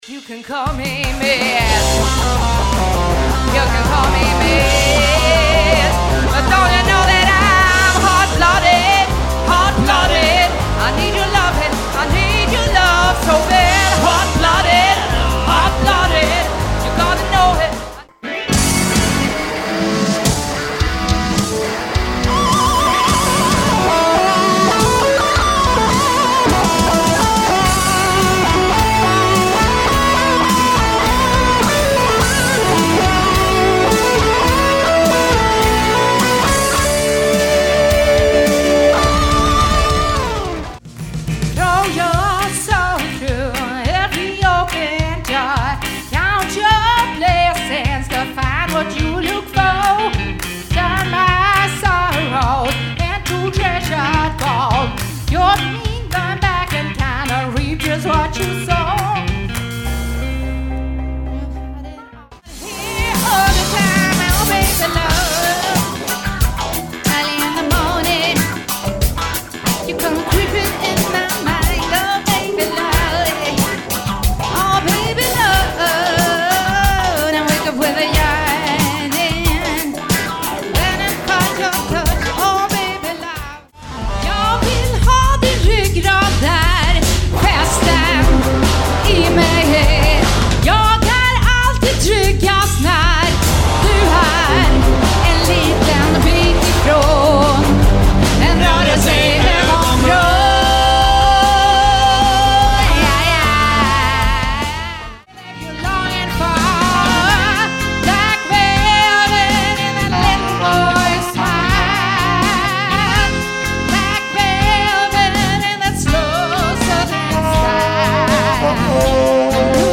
Grymt partyrockband som alltid levererar mest fest
• Coverband
• Blandade låtar (Div)